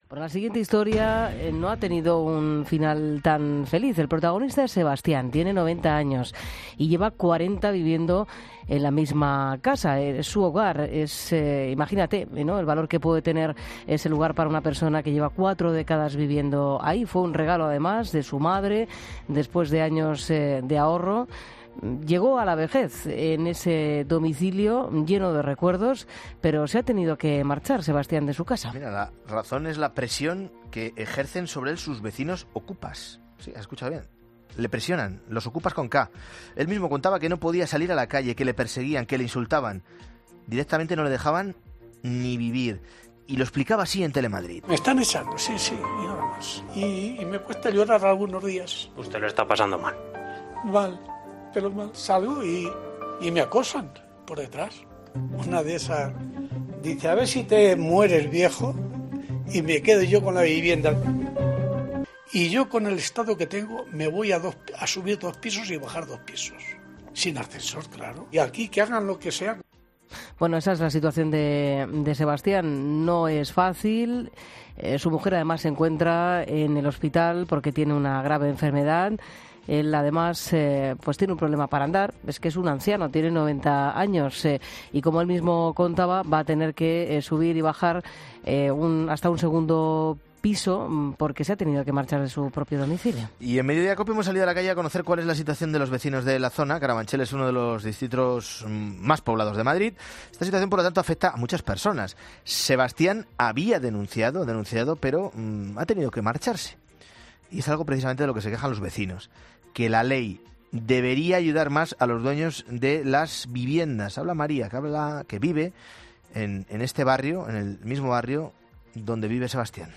En Mediodia COPE hemos salido a la calle a conocer cual es la situación de los vecinos de la zona.